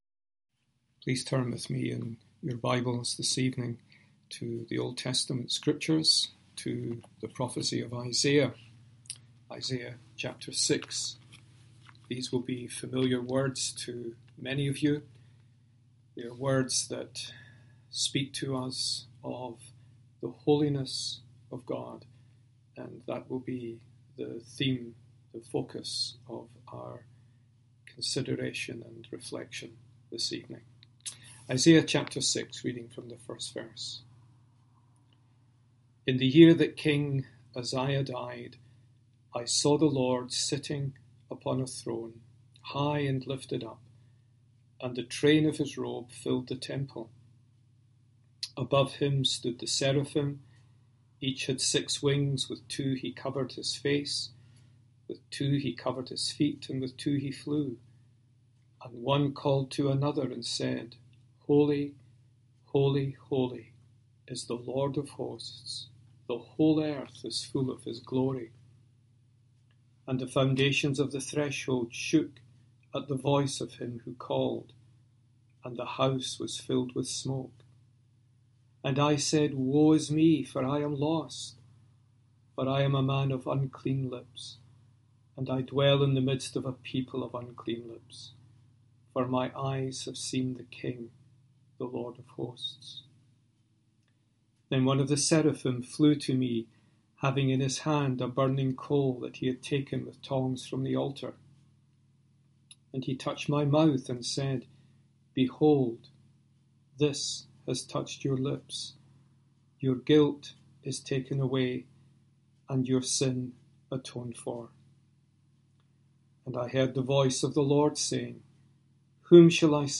Passage: Isaiah 6 Service Type: Sunday Evening Reading and Sermon